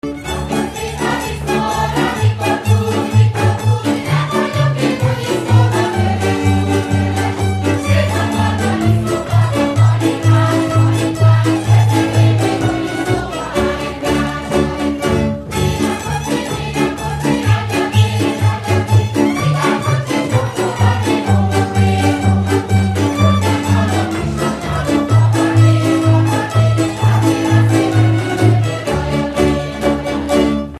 Dallampélda: Hangszeres felvétel
Alföld - Pest-Pilis-Solt-Kiskun vm. - Bogyiszló
hegedű
brácsa
tambura (prím)
tamburabrácsa
bőgő
Műfaj: Ugrós
Stílus: 4. Sirató stílusú dallamok
Kadencia: 5 (4) 2 1